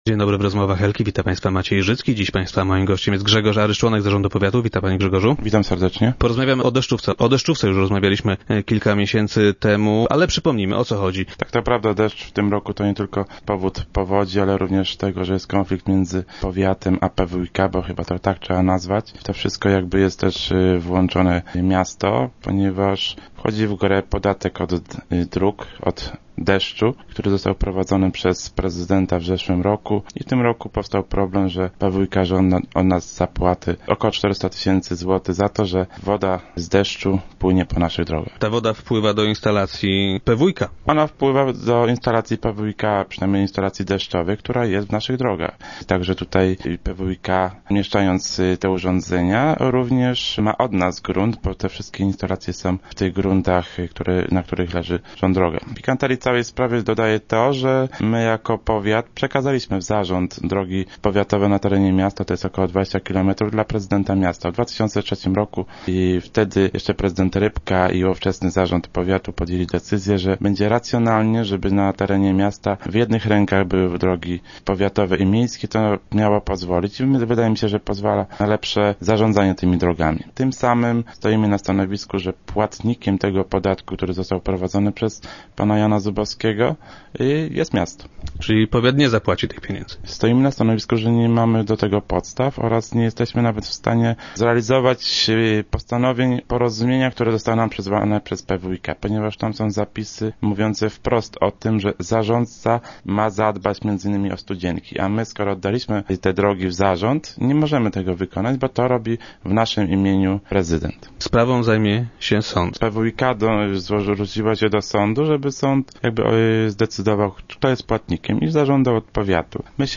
Cały ten wywiad to jedno ble ble ble .